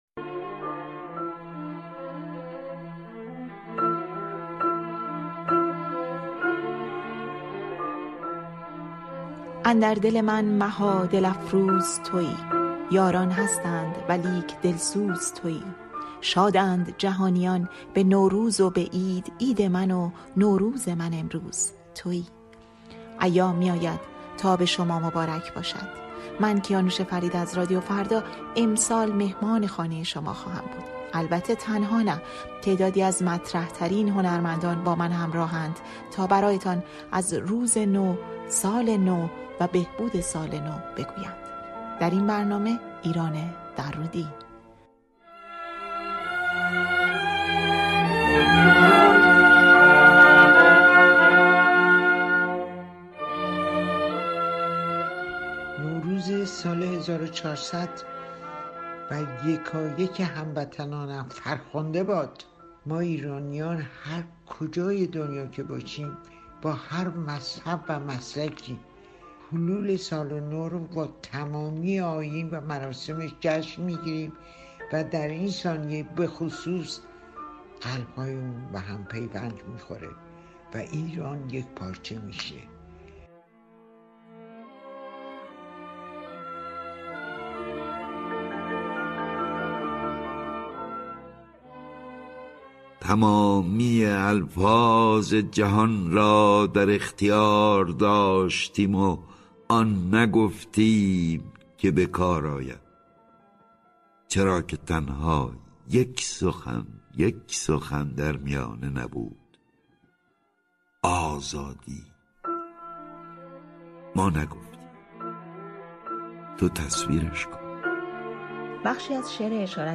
گفت‌وگوی نوروزی با ایران درودی؛ «ایران را دوست بداریم»